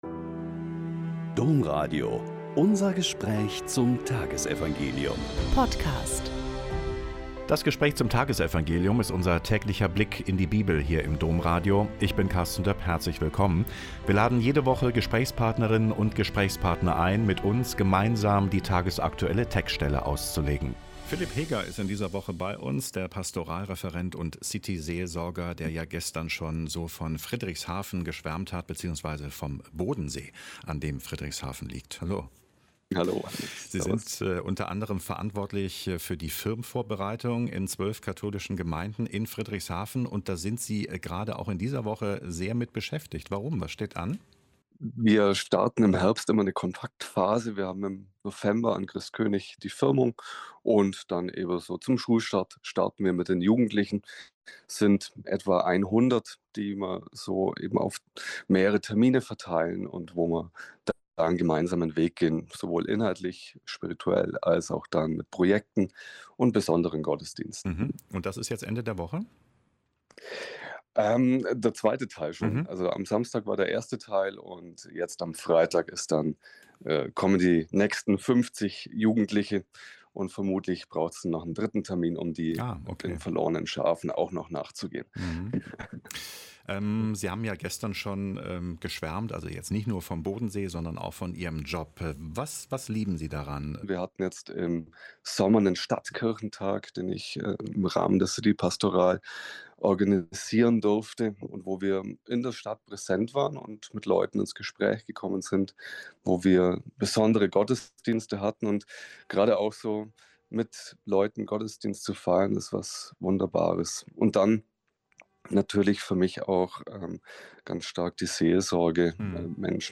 Lk 7,11-17 - Gespräch